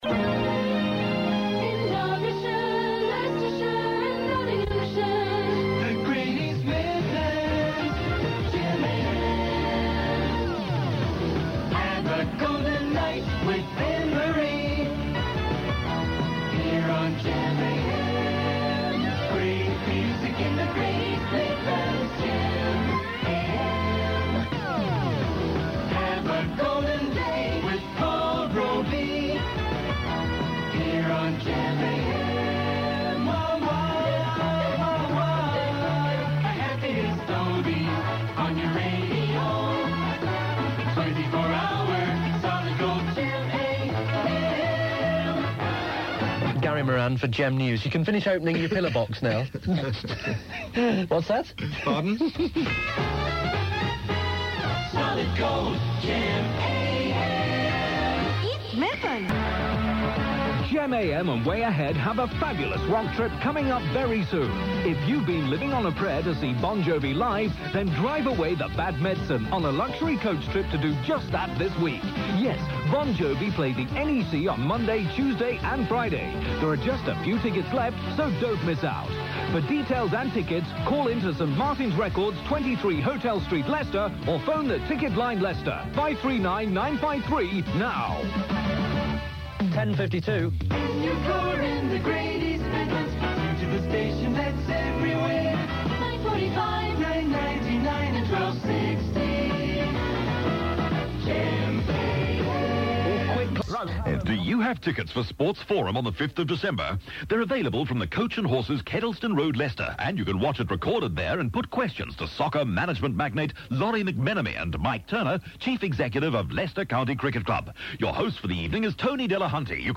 GEM AM was the medium wave service broadcast on the former medium wave frequencies of Radio Trent and Leicester Sound.
Here are some of the jingles and presenters from the first months of the station